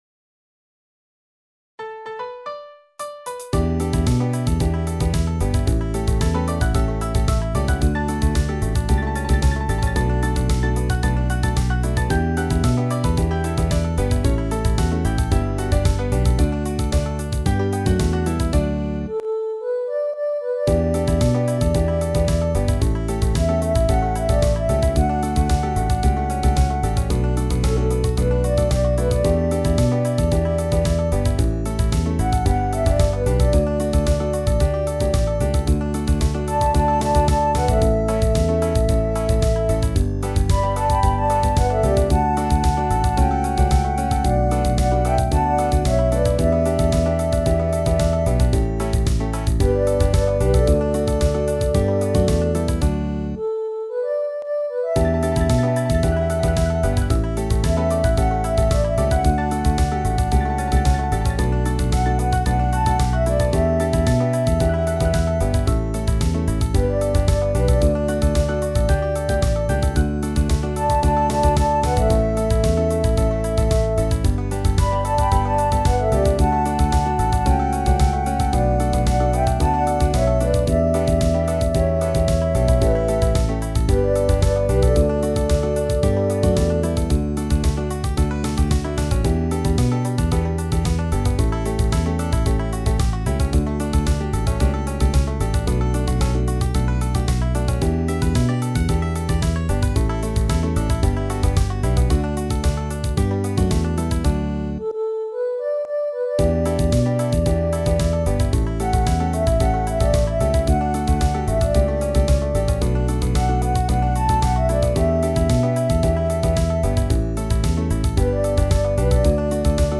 ブルーグラスから一つ、とはいっても編成は異なる。